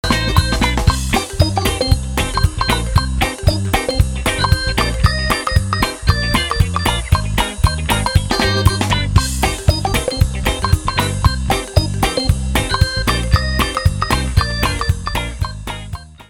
веселые
российская рок-группа, созданная в Москве в апреле 1999 года